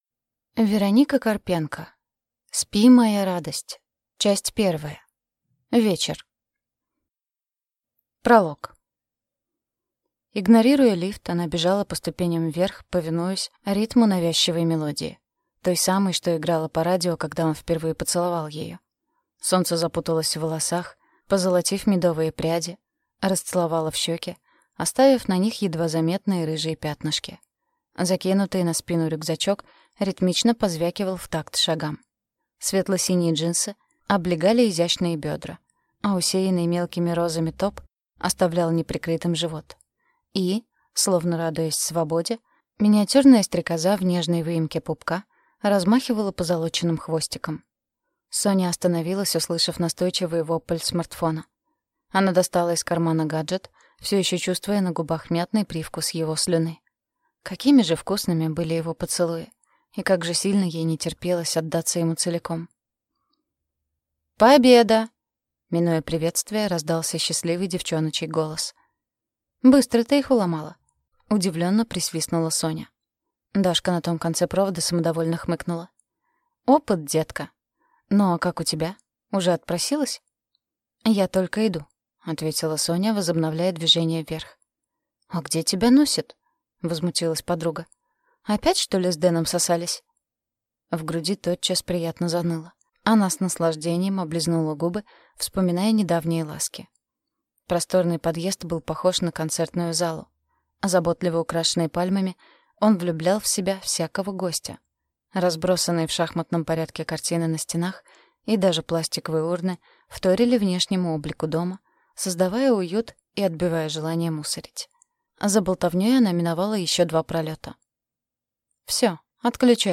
Аудиокнига Спи, моя радость. Часть 1. Вечер | Библиотека аудиокниг